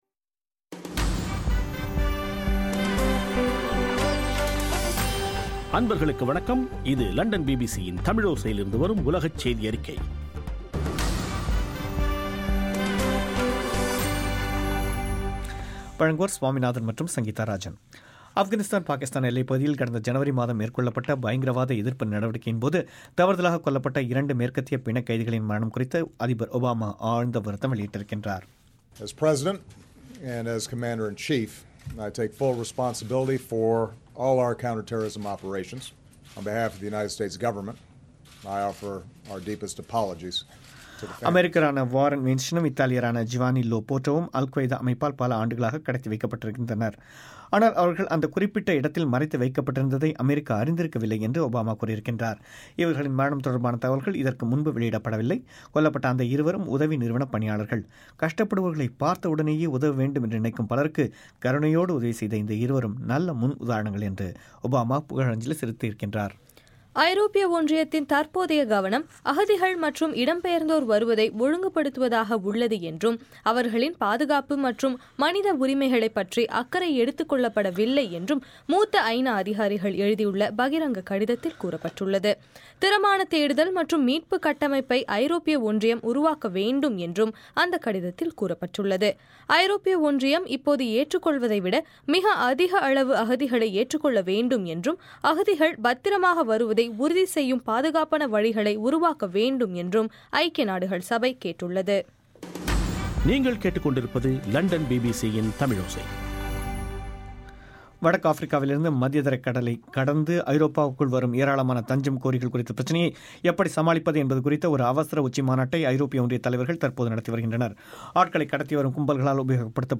ஏப்ரல் 23 பிபிசியின் உலகச் செய்திகள்